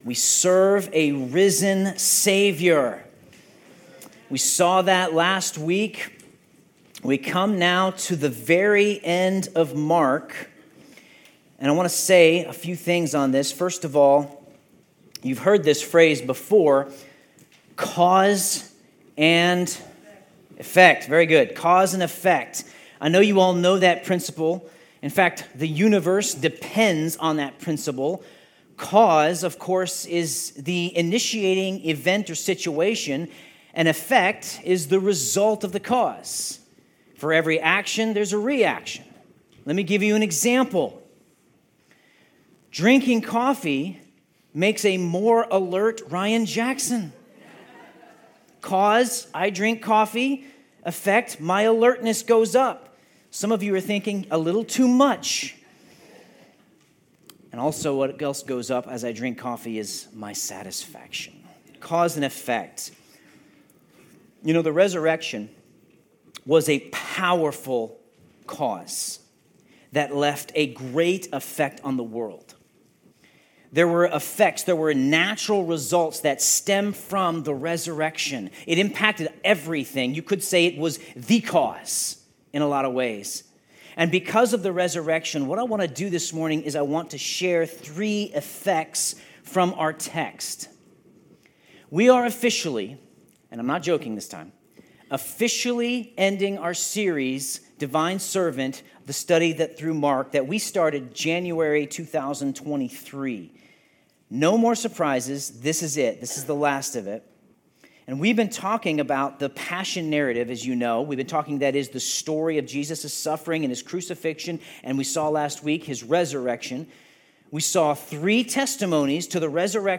Sermon Notes There are many testimonies to the resurrection of Jesus.